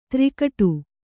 ♪ tri kaṭu